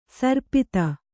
♪ sarpita